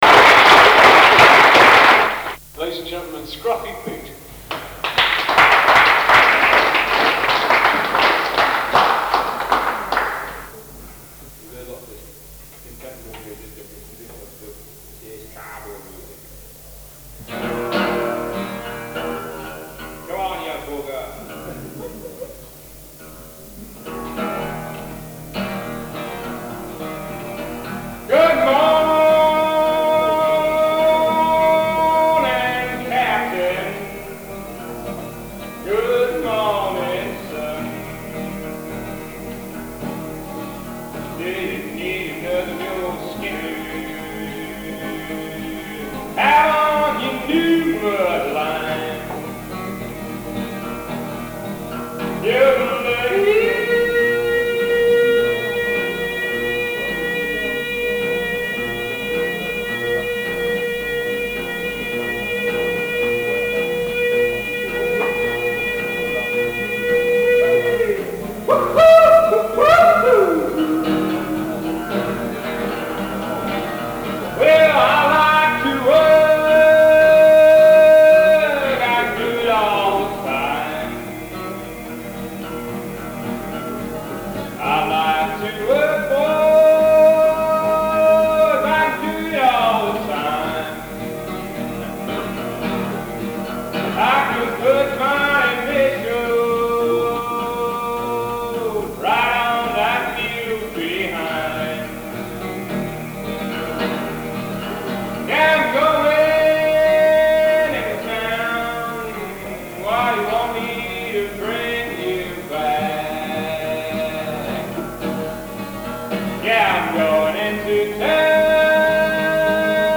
Floor singers, and invited, recorded at Harvester's Folk Club,
City of Leicester College of Education, Scraptoft, Leicester during 1976
Recorded live 1976 Harvester's Folk Club, City of Leicester College of Edication, Scraptoft, Leicester by